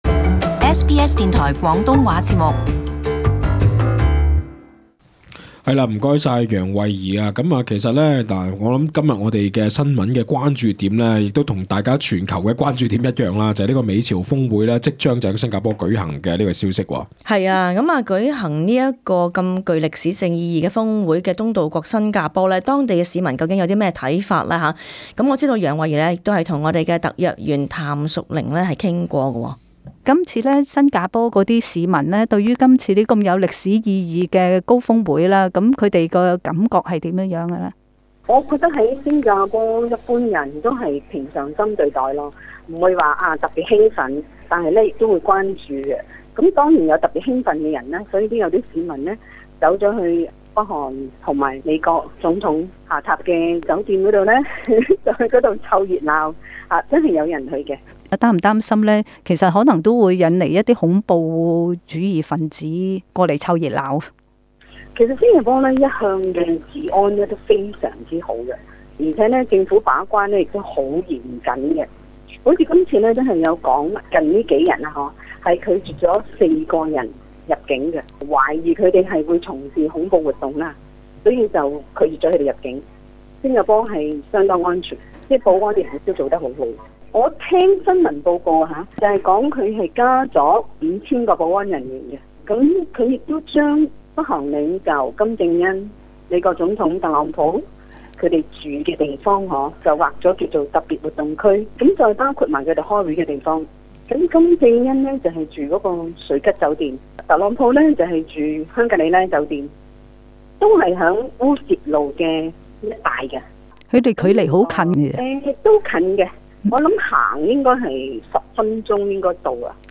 【時事專訪】美朝峰會